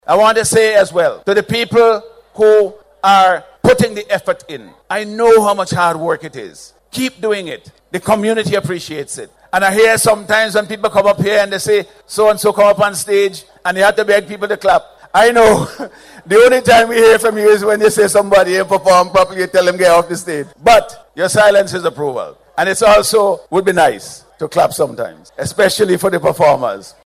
Thus commendation has come from Prime Minister, Dr. Godwin Friday while addressing the launch of the Nine Mornings Festival at Heritage Square in Kingstown.